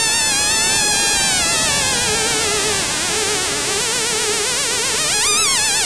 Light Sensitive Theremin w/ delay pedal